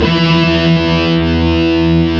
Index of /90_sSampleCDs/Roland - Rhythm Section/GTR_Distorted 1/GTR_Power Chords